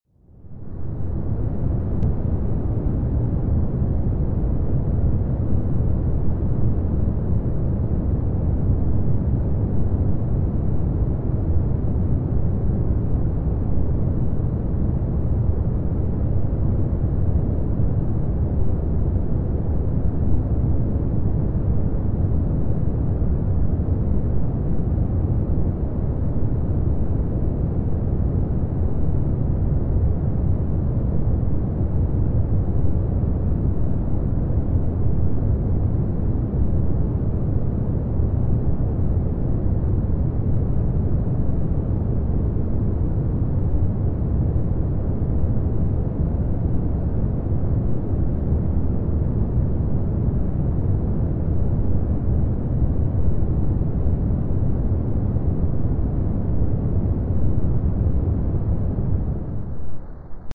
My daughter loves to listen to brown noise as she settles down.